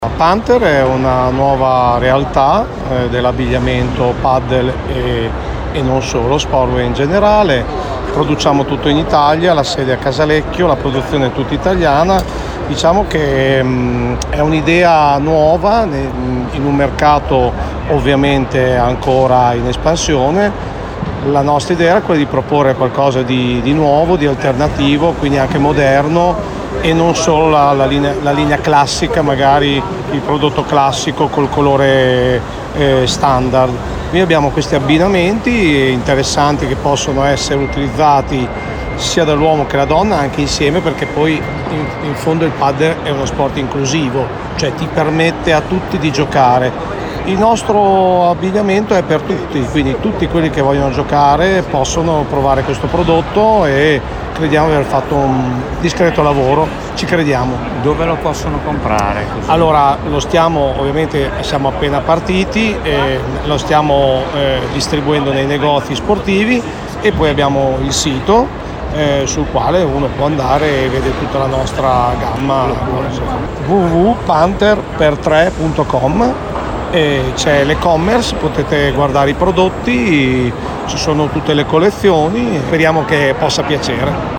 RIMINI WELNESS - Radio International Live